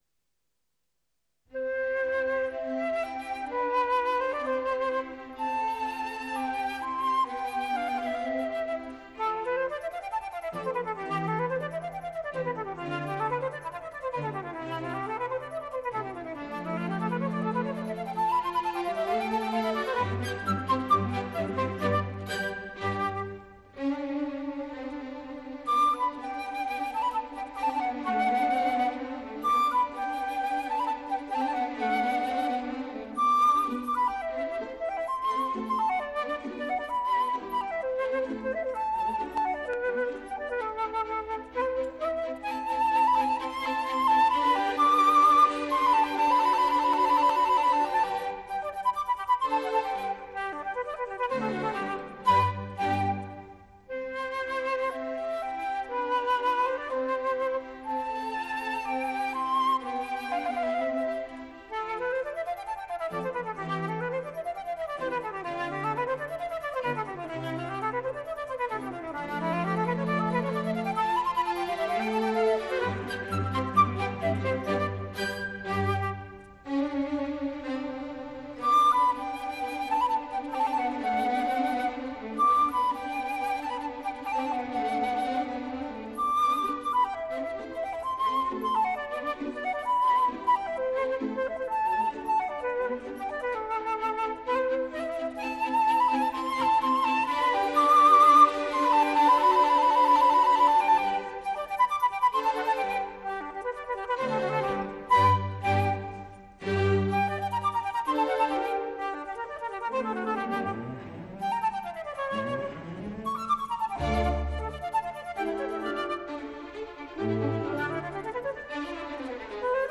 0004-长笛 短笛名曲Allegro.mp3